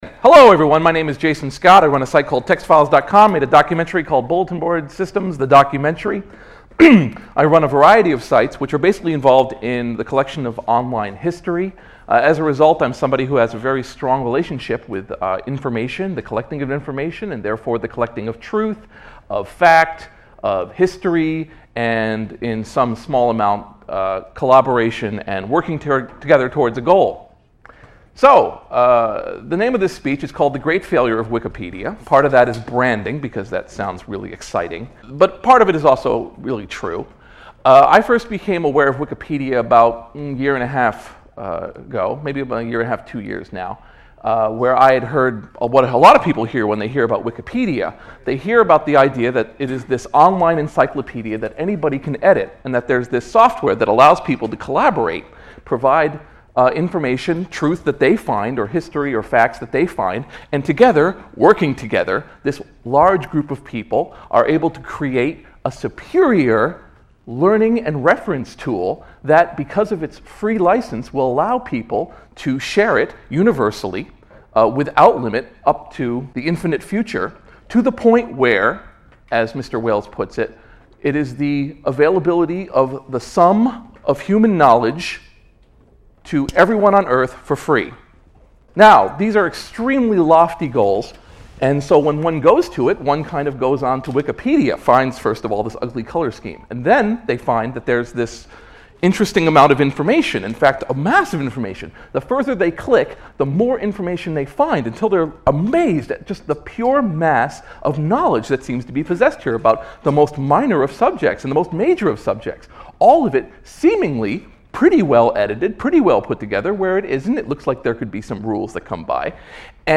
At Notacon 3 Jason Scott speaks about Wikipedia, Jimmy Wales design choices and their consequences.